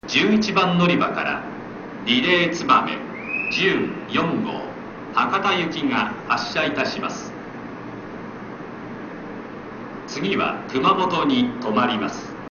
また、JR九州初の発車メロディが使用されています。
ホームドア開閉時には、駅員が注意放送をする為、発車放送、到着案内放送では駅員放送が被ります。
発車メロディは「風は南から」が流れます。基本的には1コーラスしか鳴りませんが、2コーラス目に入ることもあります。
スピーカーはUNI-PEX　箱型です。